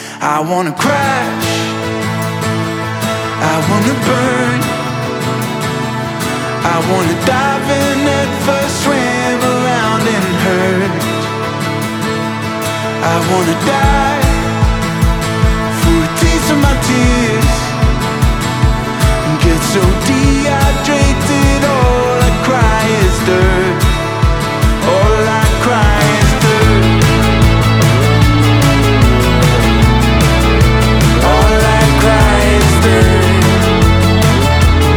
Indie Pop Alternative Country
Жанр: Поп музыка / Альтернатива / Кантри